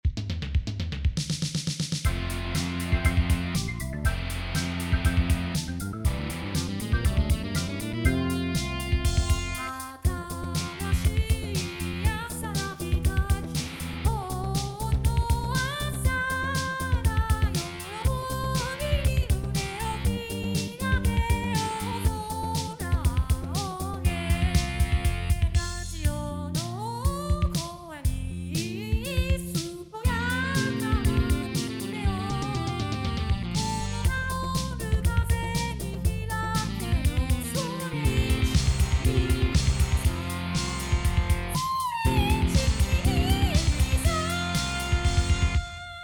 この前から少しずつ遊び始めたDTM。